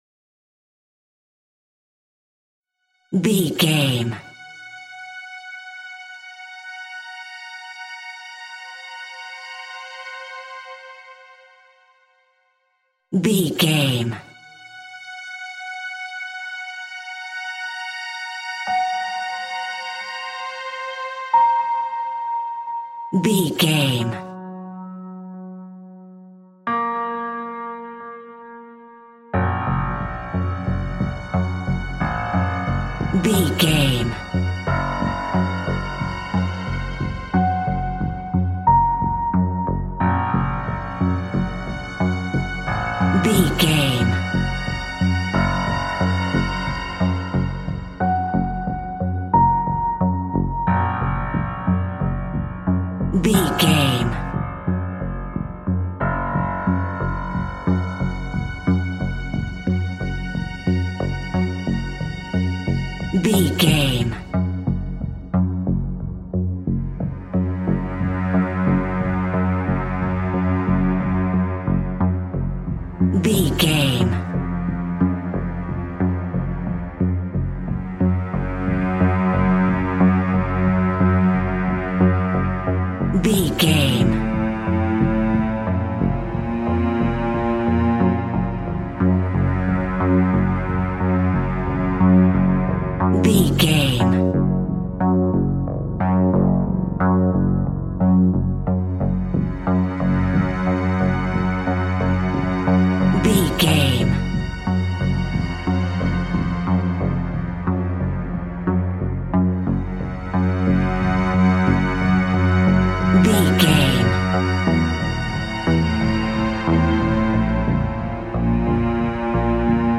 Horror Atmos Thriller Cue.
Diminished
G♭
ominous
eerie
strings
piano
synthesiser
horror music
Horror Pads